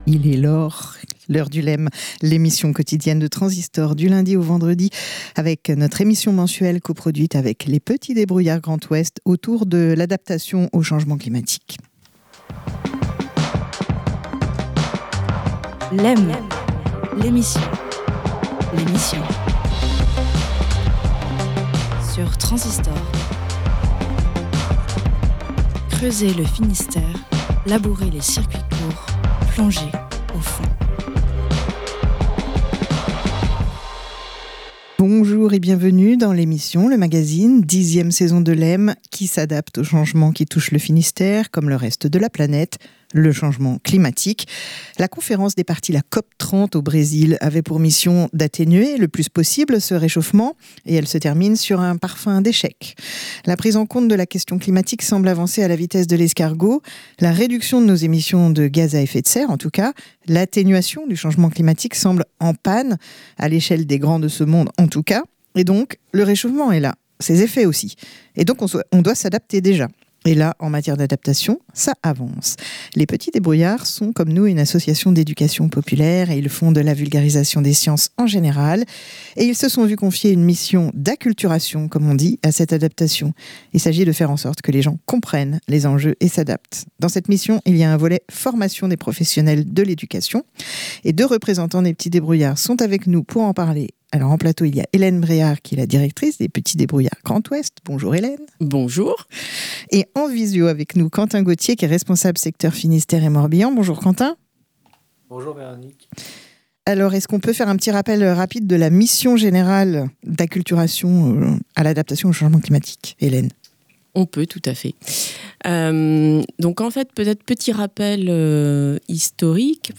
On a déjà évoqué sur nos ondes le volet "grand public" de l'appel à projets FEDER ; une fois par mois dans Lem, nous recevons des animateurices des Petits débrouillards Grand ouest et leurs partenaires autour des adaptations au changement climatique.